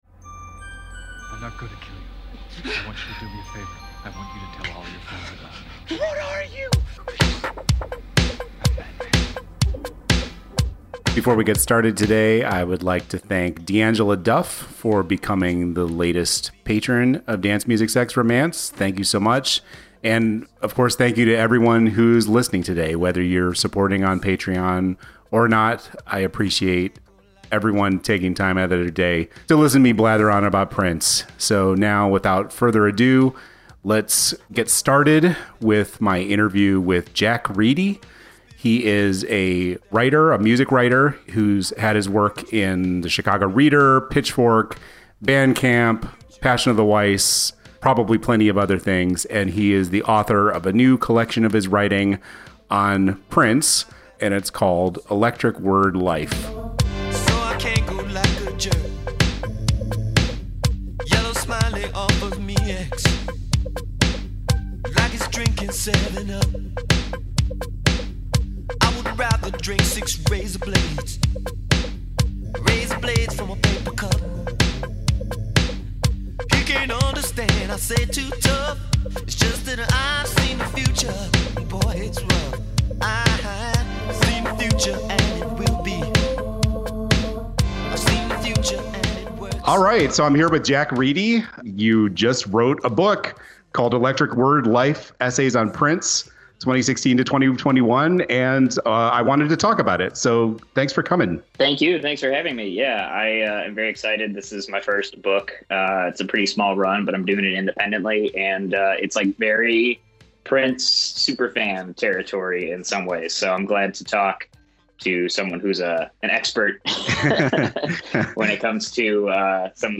It was a really fun conversation, running through each of the pieces collected in his book and covering everything from Prince’s influence on Chicago house to the degree to which the Batman album goes (spoiler: it’s hard).